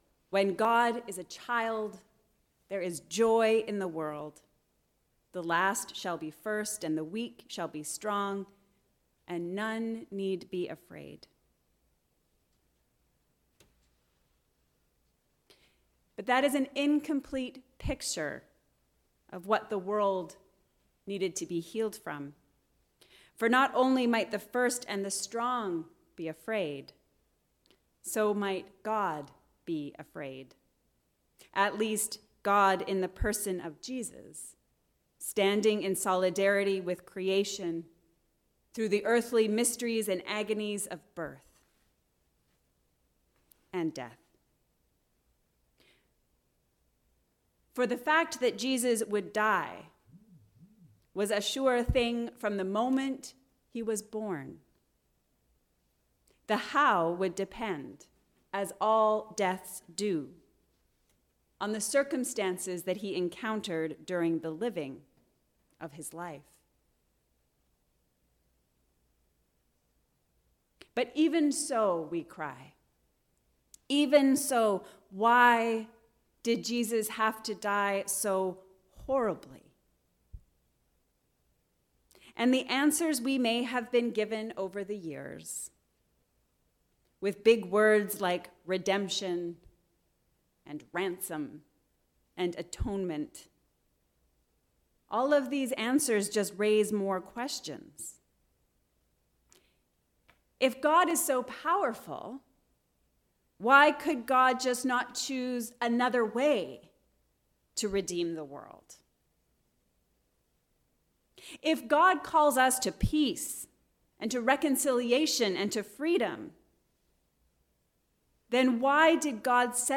God’s love is stronger. A sermon for Good Friday